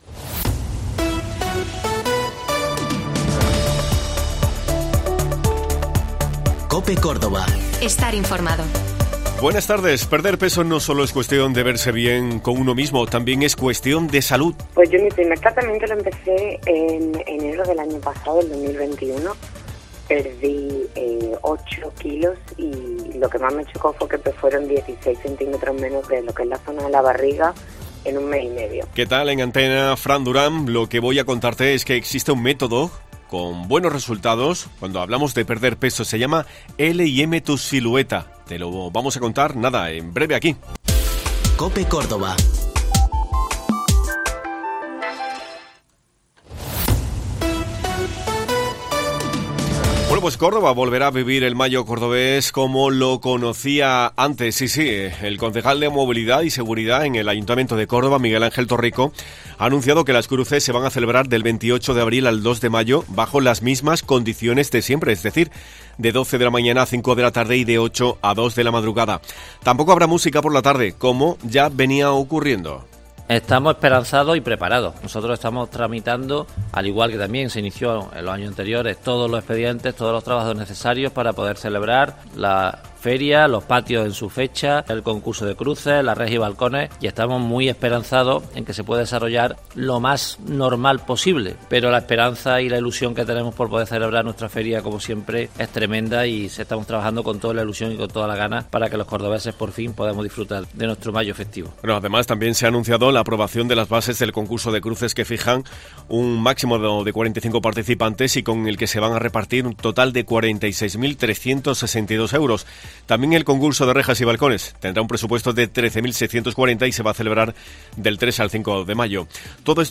Escucha Mediodía COPE en Córdoba en el 87.6 FM, 1215 OM y en la App de COPE, disponible gratuitamente para iOS y Android.